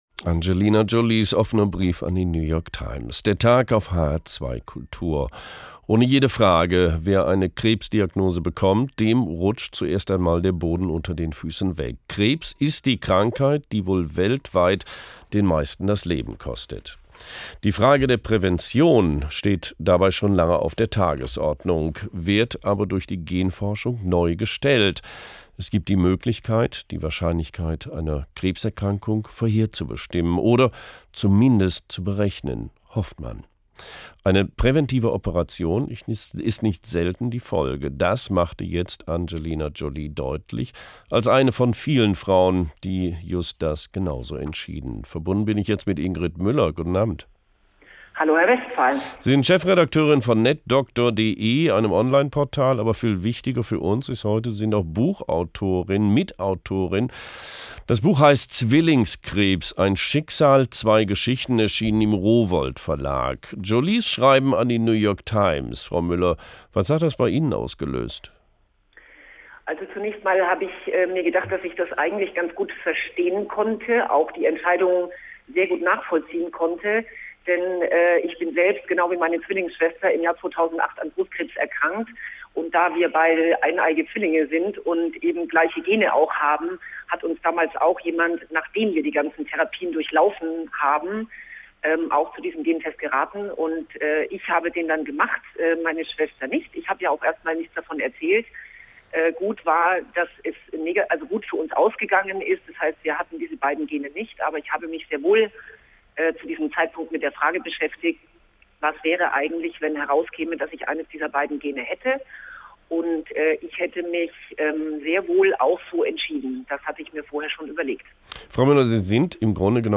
HR2 – der Tag: Interview